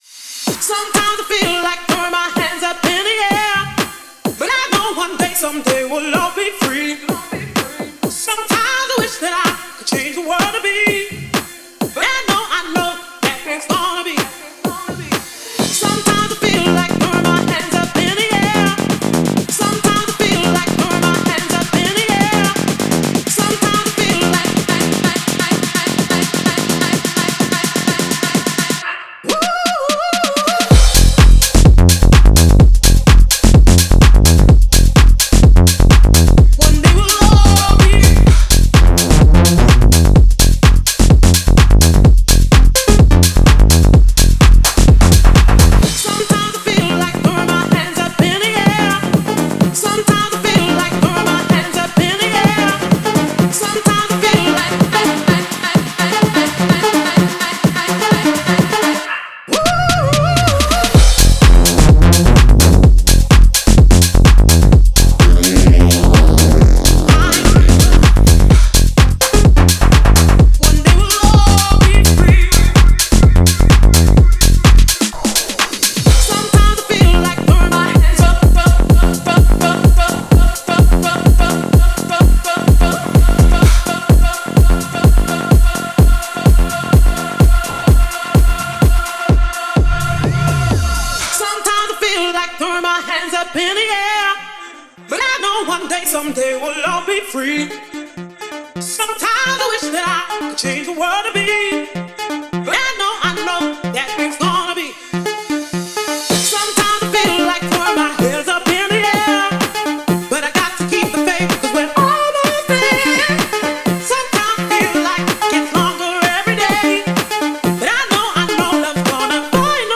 энергичная трек в жанре хаус